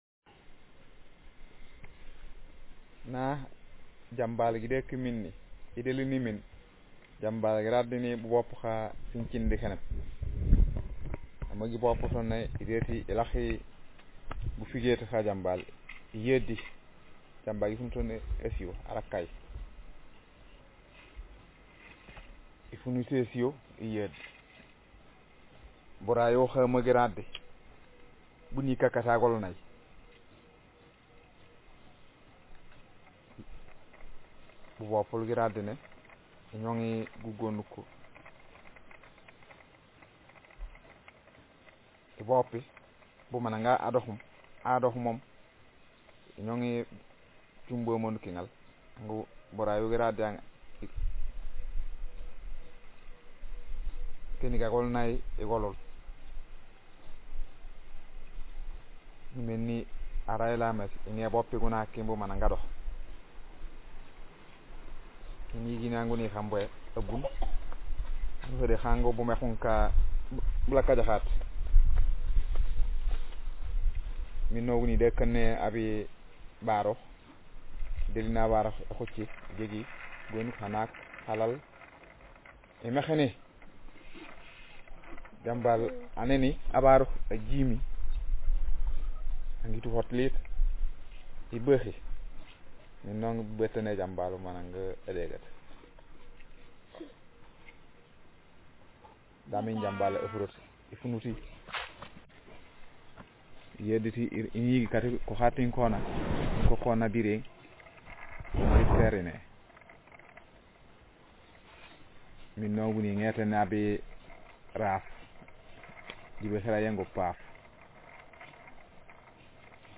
Speaker sex m Text genre procedural